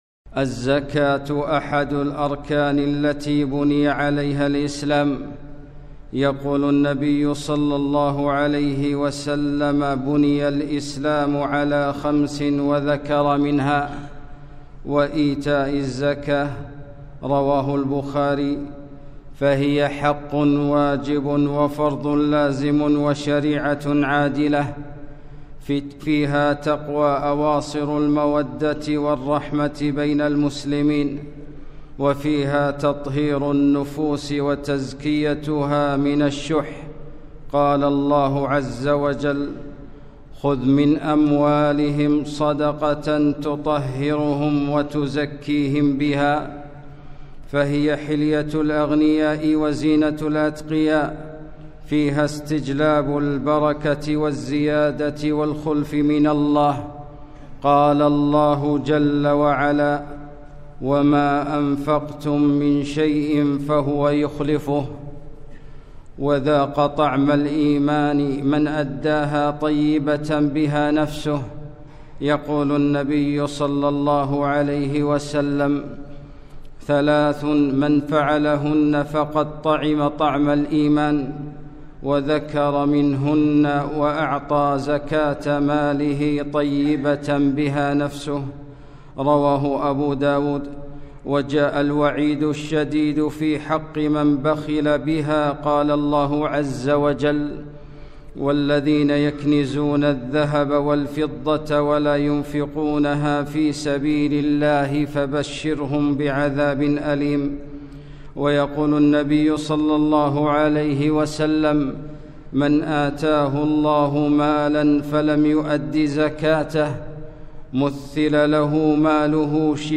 خطبة - الركن الثالث